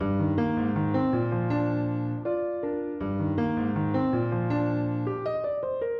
RNB钢琴80bpm的Amajor 2
Tag: 80 bpm RnB Loops Piano Loops 1.01 MB wav Key : A